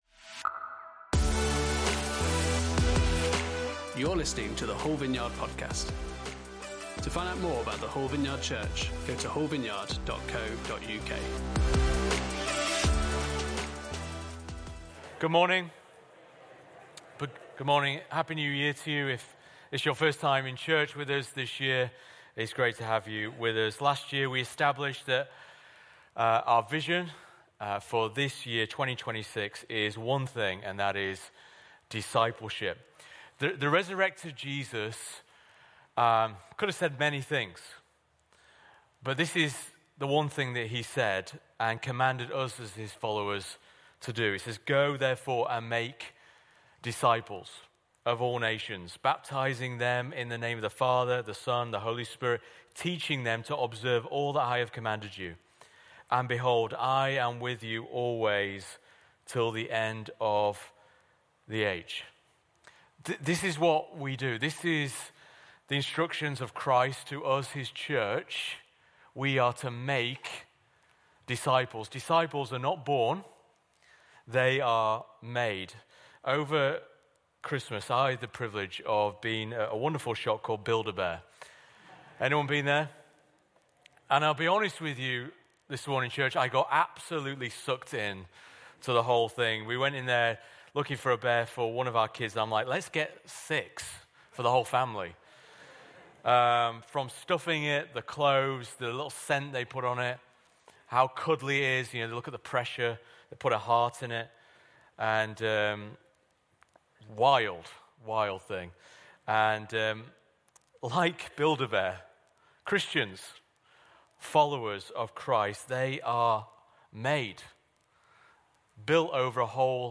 Series: Vision 2026 // Discipleship Service Type: Sunday Service